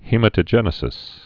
(hēmə-tə-jĕnĭ-sĭs, hĭ-mătə-)